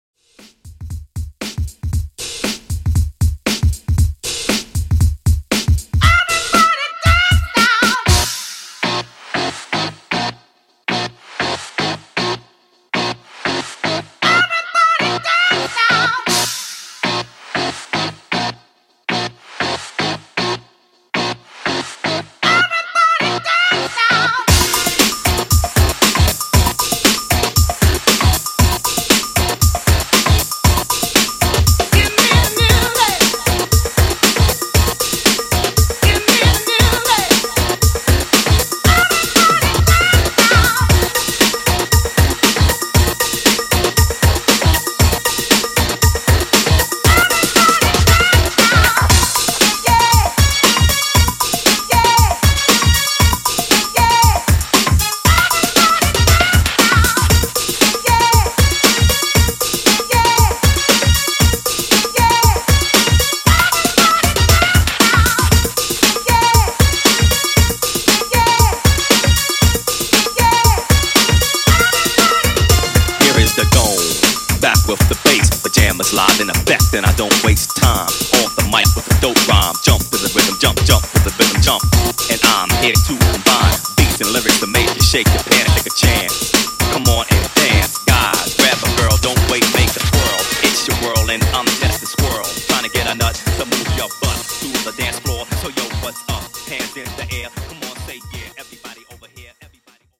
90s Dance Redrum)Date Added